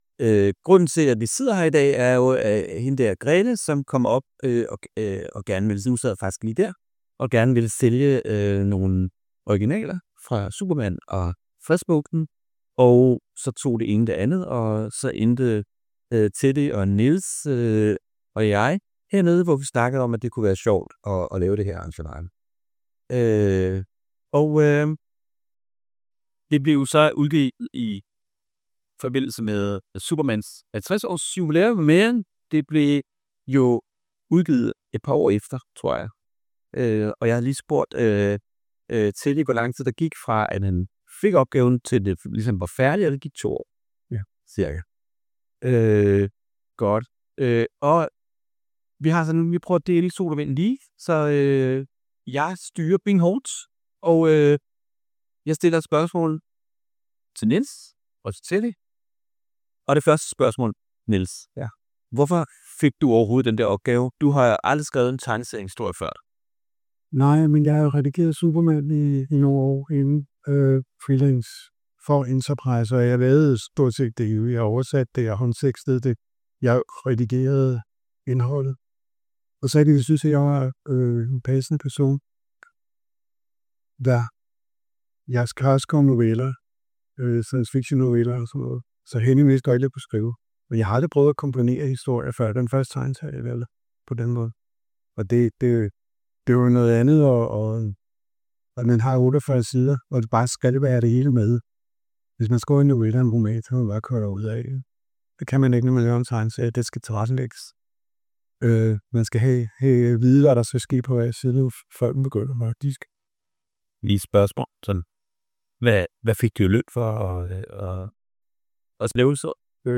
Det ligger nu på Youtube og her kan du høre det hele med pletvis forbedret lyd.